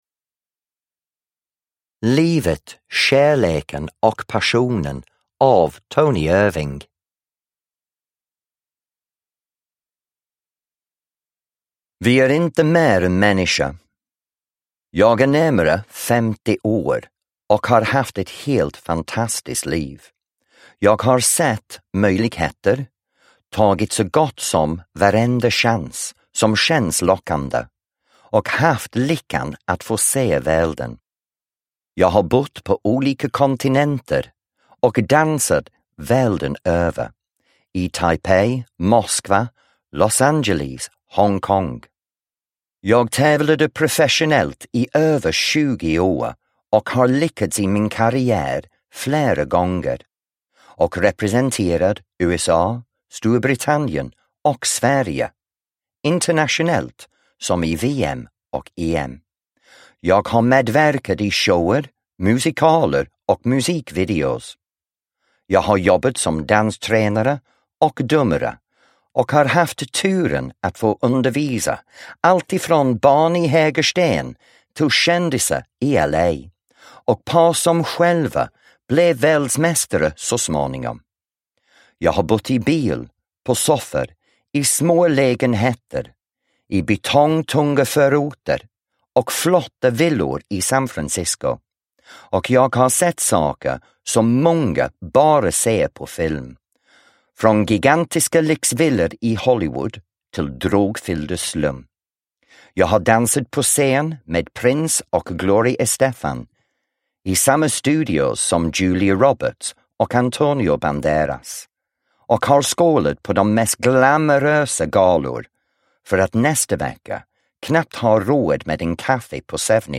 Livet, kärleken & passionen – Ljudbok – Laddas ner
Uppläsare: Tony Irving